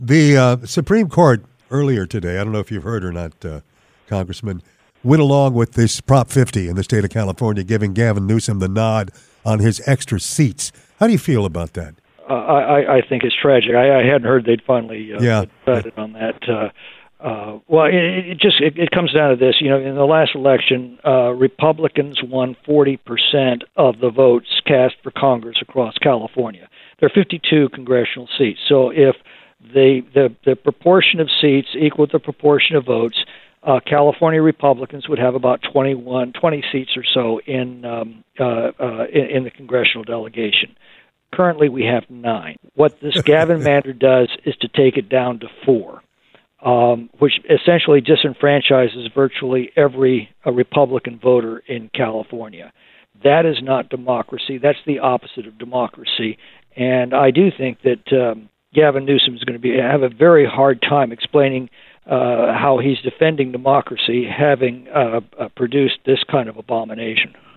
In an exclusive interview